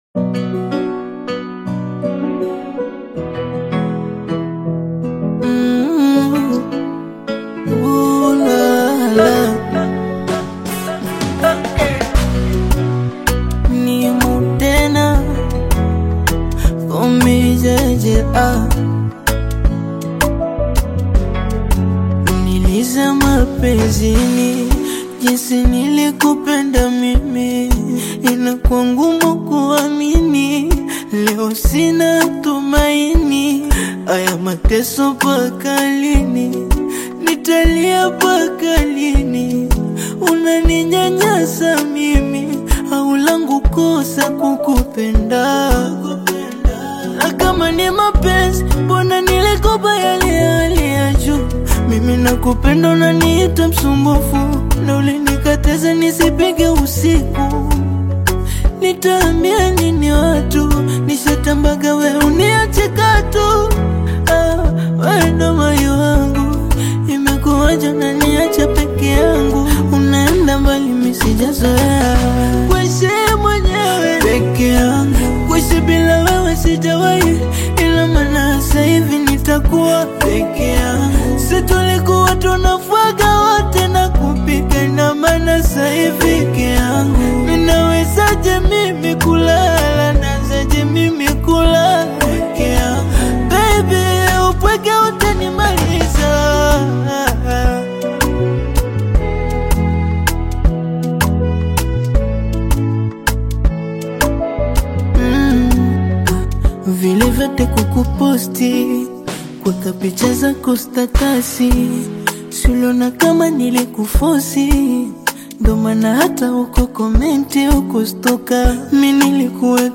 heartfelt Afro-Beats/Bongo Flava single